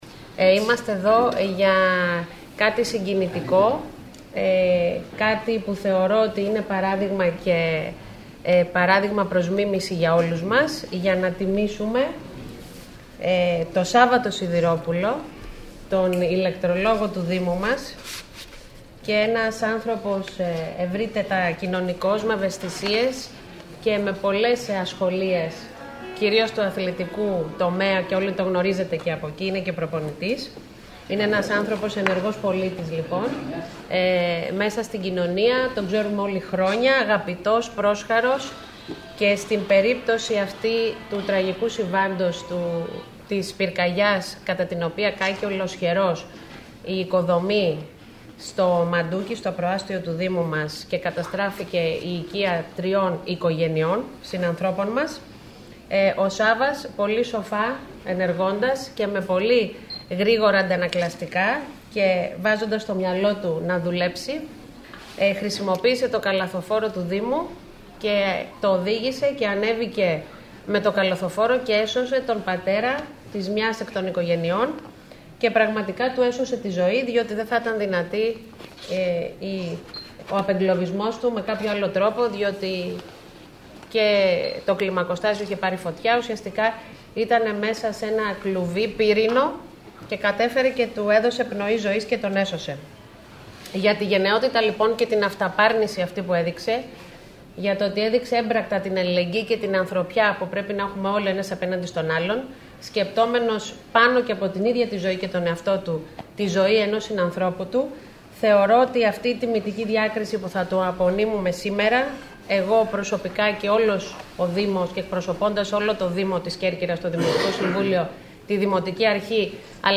Στην τελετή, που πραγματοποιήθηκε στο ιστορικό Δημαρχείο Σαν Τζιάκομο, συμμετείχαν το σωματείο των δημοτικών υπαλλήλων, η τοπική ηγεσία της πυροσβεστικής, αντιδήμαρχοι και εργαζόμενοι στο Δήμο Κεντρικής Κέρκυρας.